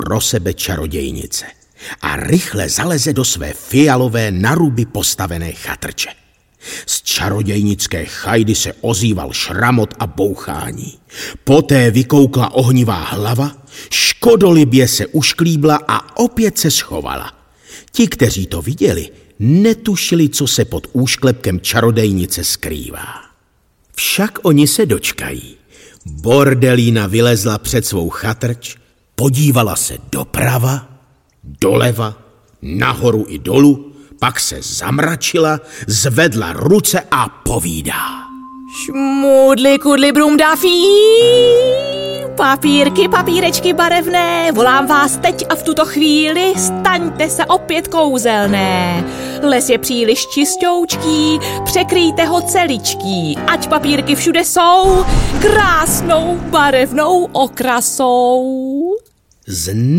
Audiobook
Read: Lucie Vondráčková